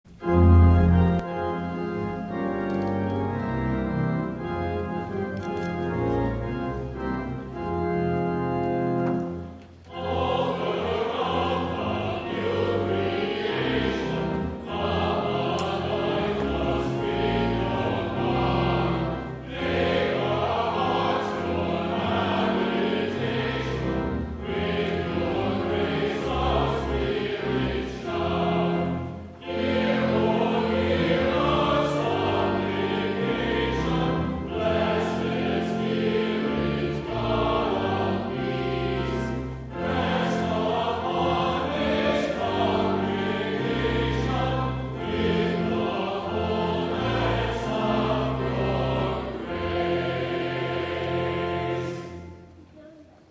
11 A.M. WORSHIP
*THE CHORAL RESPONSE